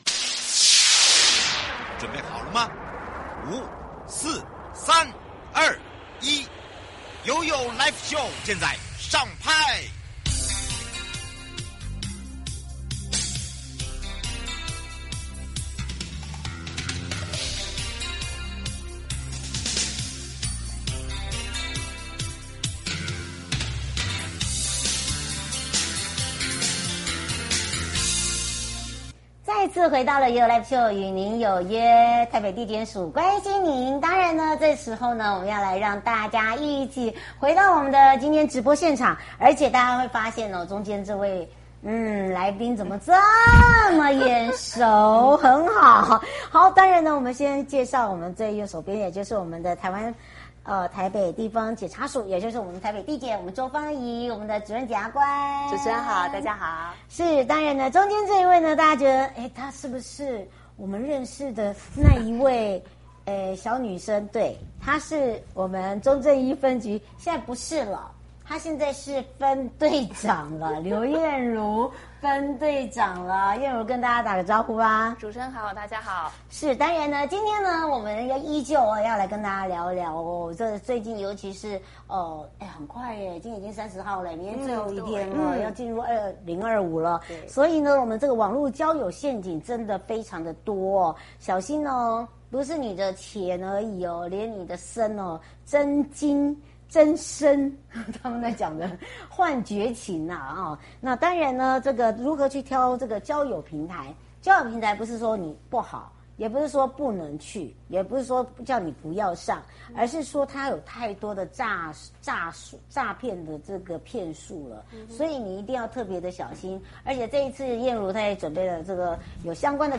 直播